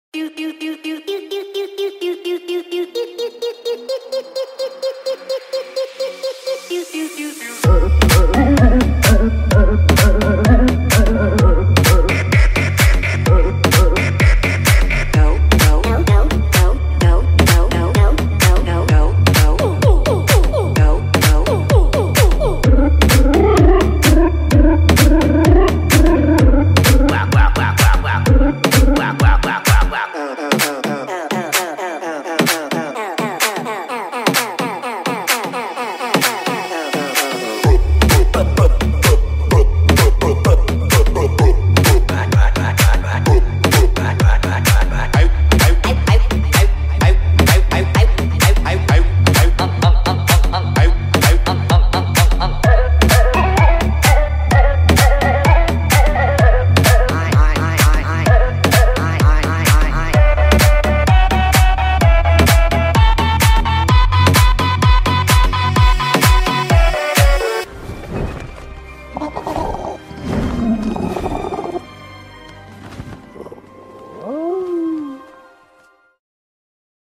(COVER)